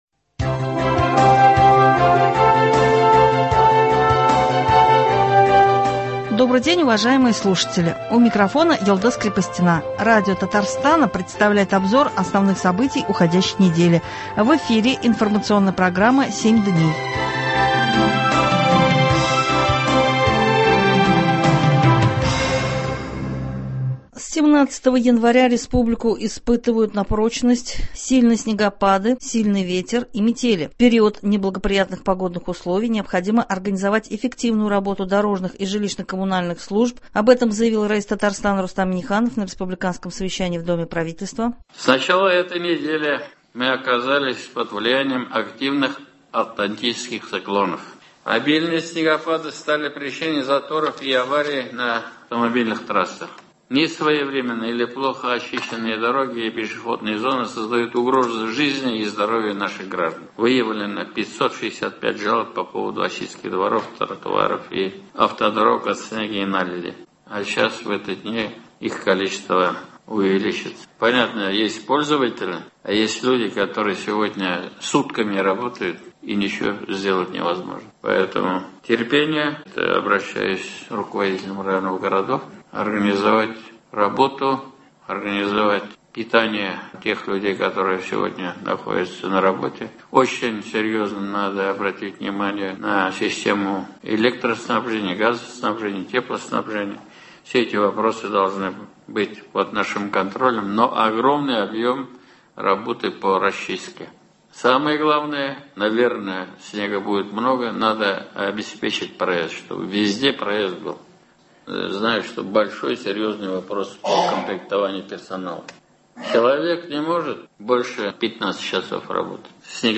Обзор событий. Татарстан борется с непогодой.